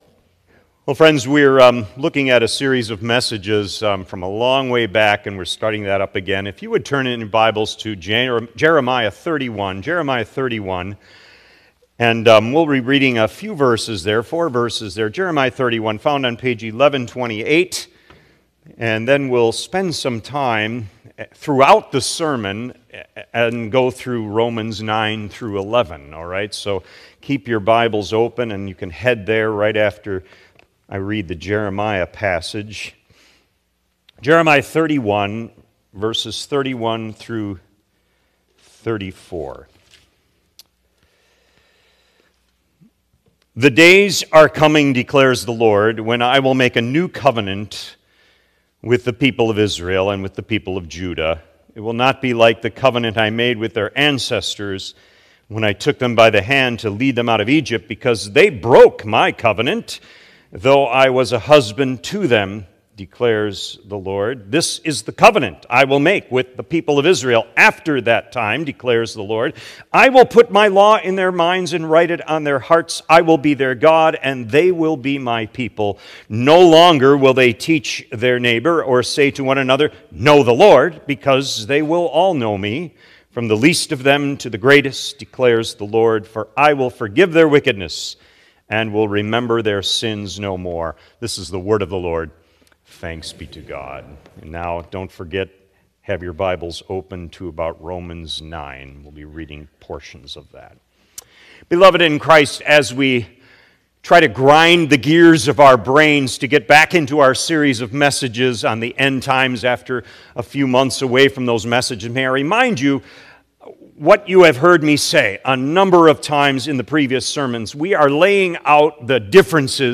“The New Covenant” September 28 2025 P.M. Service